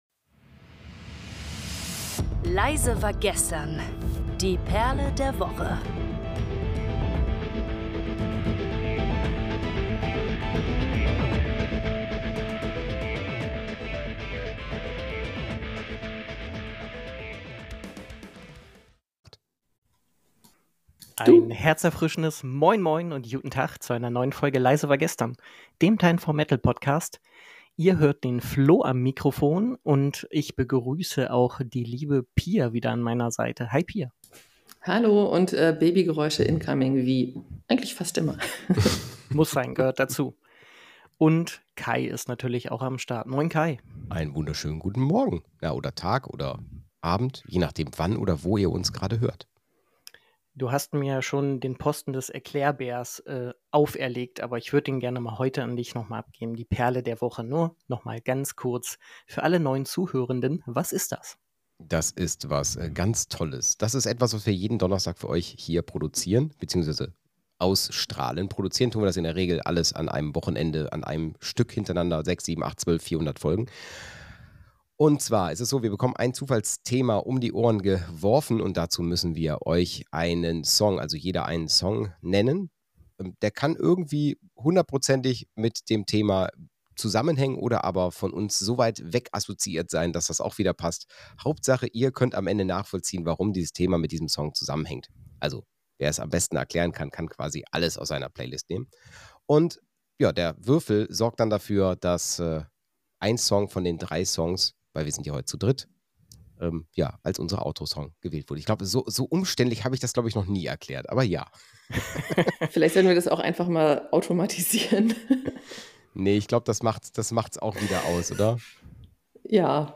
In dieser Folge des Metal Podcasts geht es um das Thema Black Metal. Die Moderatoren stellen jeweils einen Song vor, der entweder direkt mit dem Thema zusammenhängt oder assoziativ damit verbunden ist. Es wird auch über das Genre des Post-Black Metal und die Band Cradle of Filth diskutiert.